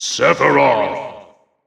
The announcer saying Sephiroth's name in English and Japanese releases of Super Smash Bros. Ultimate.
Sephiroth_English_Announcer_SSBU.wav